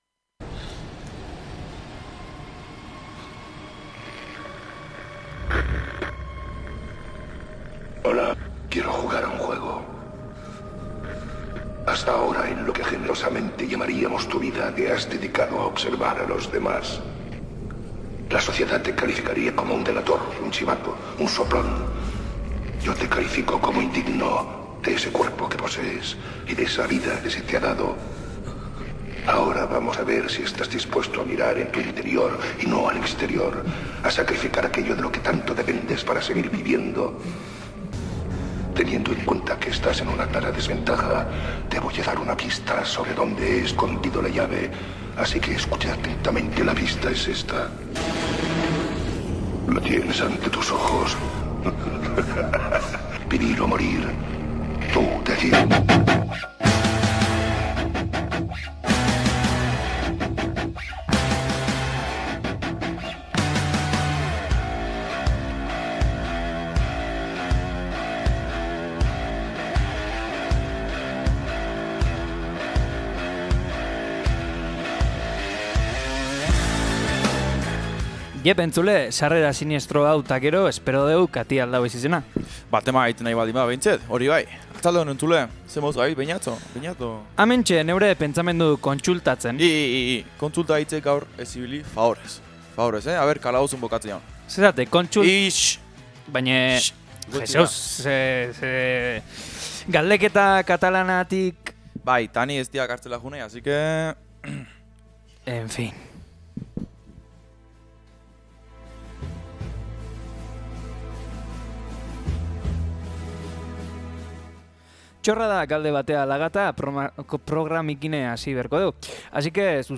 Musikaren atal gogorre edukiko dugu, bertan torturatzeko erabili den musika edukiko dugu entsungai. Gero astero bezala, lata putie emango deu a lo loko atalean gidoirik gabe iritzire hitz egiten.